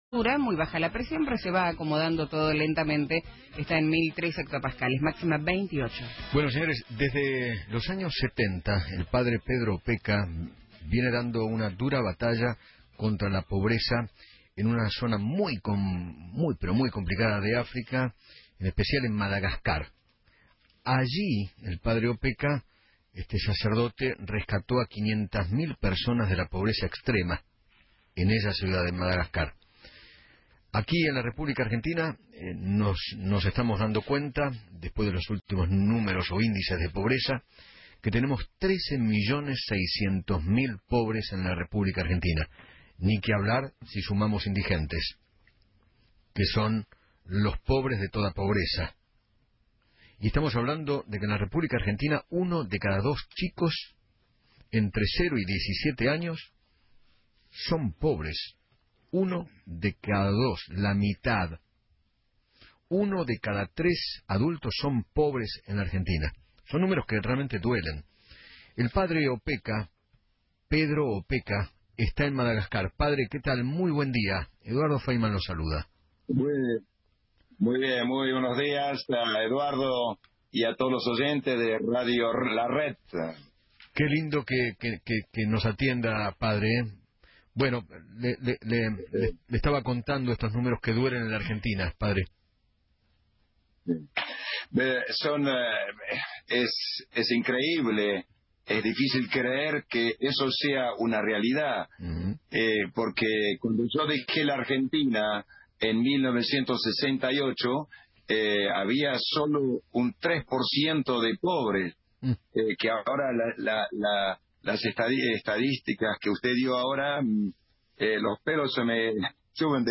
El Padre Pedro Opeka habló con Eduardo Feinmann desde Madagascar, donde realiza su misión de sacar a miles de personas de la pobreza y dijo que  “Los números de la Argentina me dan vergüenza, como un país rico puede vivir esa situación.
Padre-Opeka.mp3